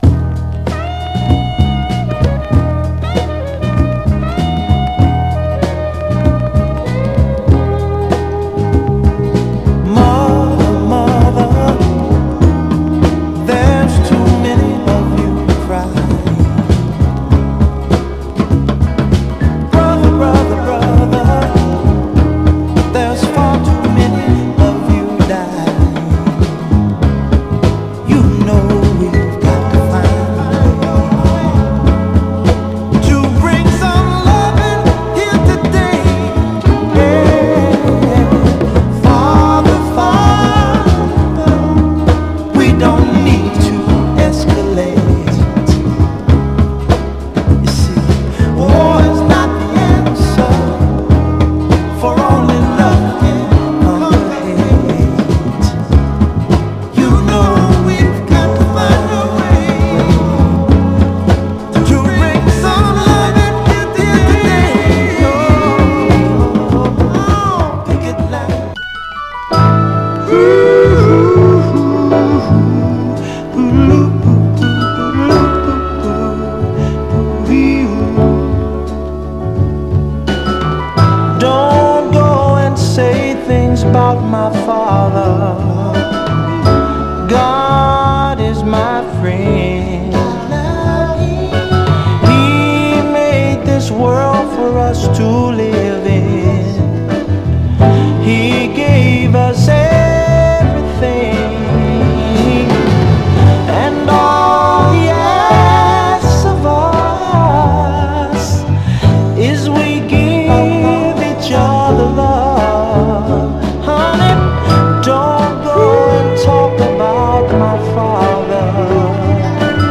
LPヴァージョンももちろん良いですが、別テイクとなるこの7インチ・シングル・ヴァージョンも最高です！
※試聴音源は実際にお送りする商品から録音したものです※